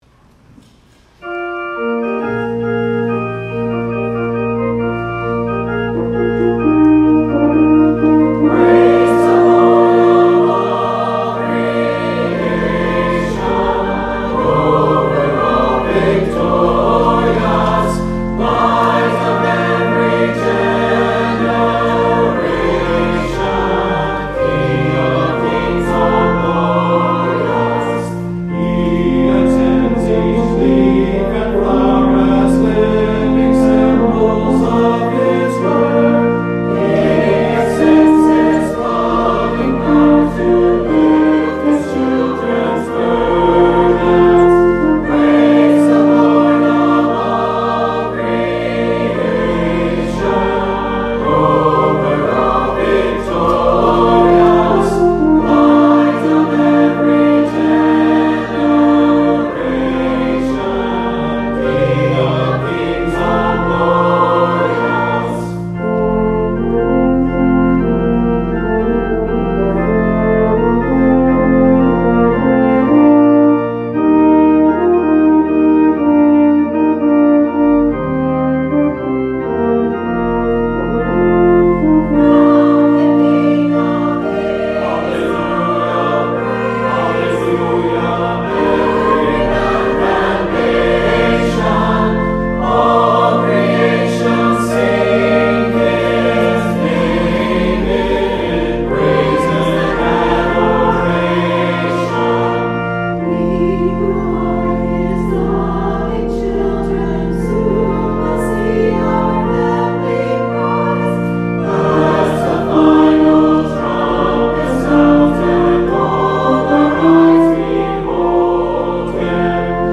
Offertory: Trinity Chancel Choir